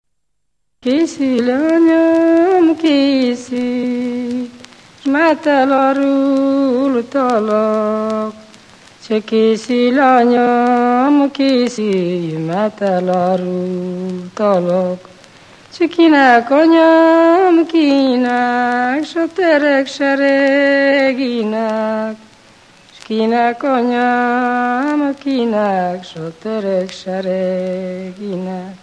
Moldva és Bukovina - Moldva - Gajcsána
Műfaj: Ballada
Stílus: 3. Pszalmodizáló stílusú dallamok
Szótagszám: 6.6.6.6
Kadencia: 4 (b3) 1 1